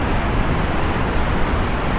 falls.ogg